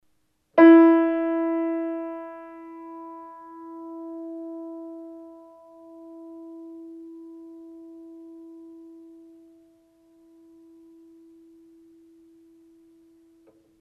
Kawai Piano (not perfectly tuned)
piano_E.mp3